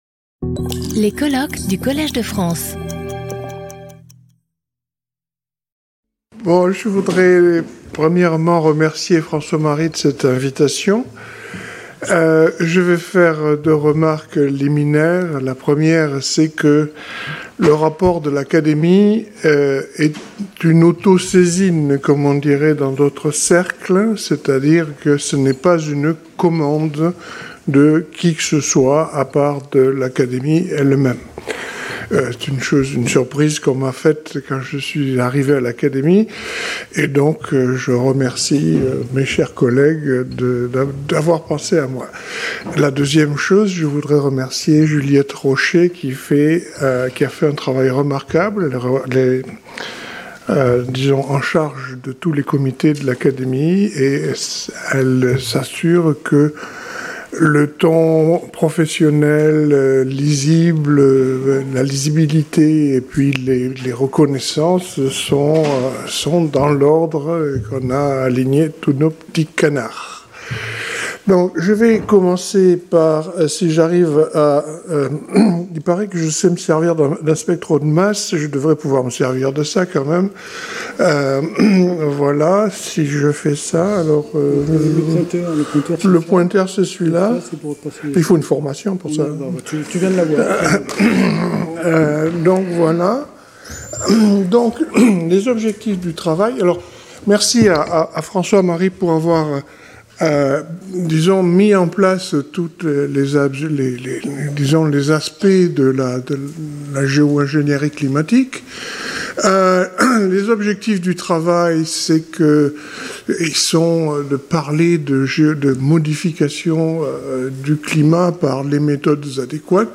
Sauter le player vidéo Youtube Écouter l'audio Télécharger l'audio Lecture audio Résumé This presentation explores geoengineering – a set of ideas aimed at cooling the planet if cutting greenhouse gas emissions isn’t enough to stop climate change. It looks at two main strategies: Solar Radiation Management (SRM) and Carbon Dioxide Removal (CDR).